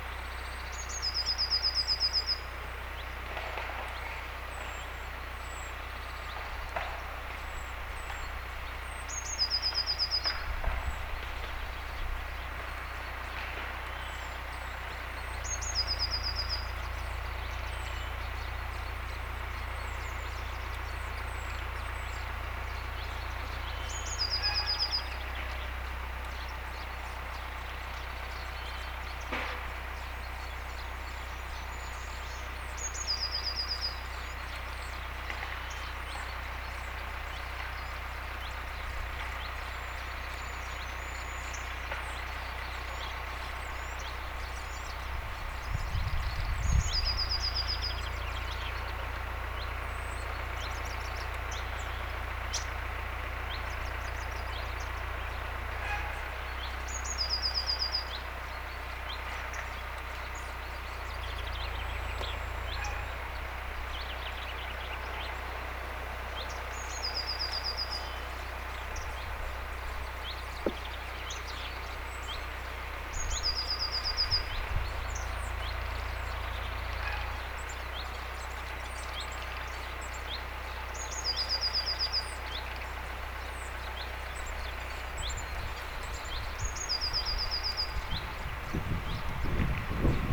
taustalla laulaa kevään ensimmäinen
järripeippo
taustalla_laulaa_kevaan_ensimmainen_jarripeippo.mp3